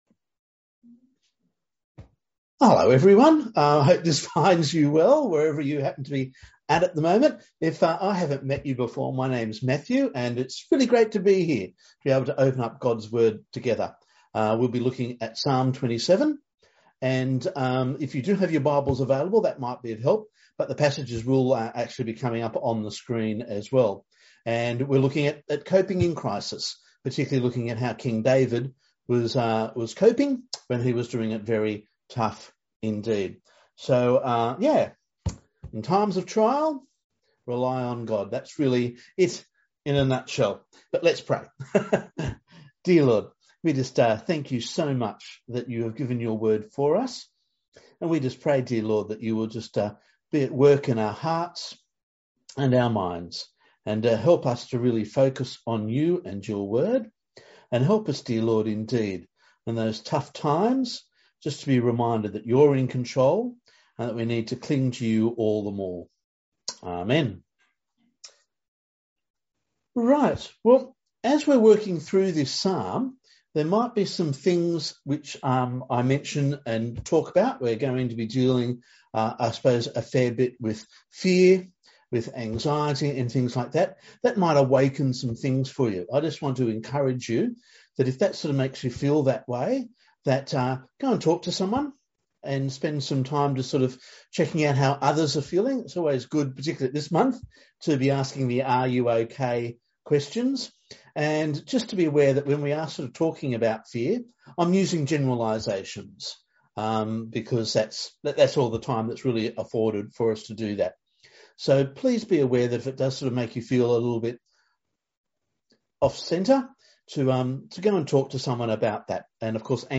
or click the ‘Download Sermon’ button above or press ‘play’ in the audio bar for an audio-only version